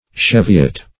Cheviot \Chev"i*ot\, n.